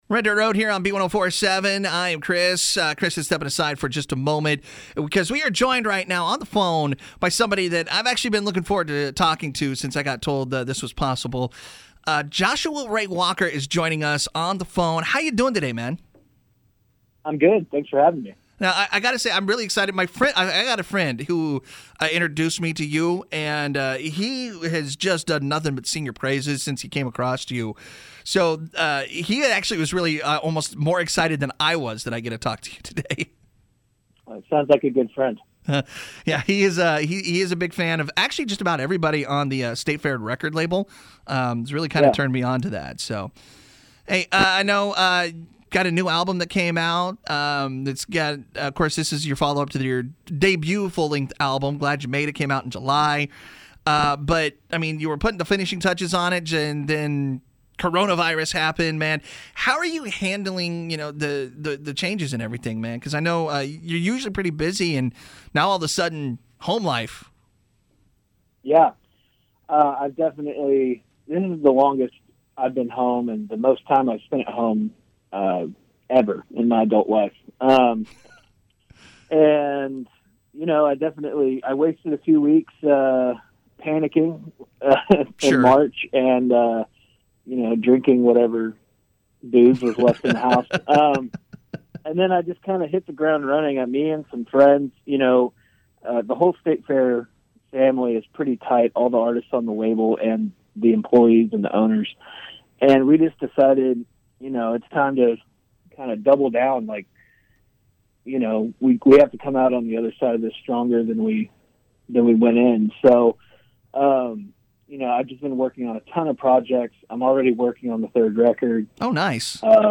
Artist Interview